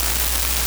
¿Ese crujido? Son 1.177 microvacilaciones individuales.
• Textura: Granular / Frágil
Si escuchas atentamente, puedes oír el zumbido de 60 Hz de la línea base intentando mantener la estructura unida, pero las fracturas por estrés están atravesando el piso de ruido. Suena como un contador Geiger en una habitación llena de malas decisiones.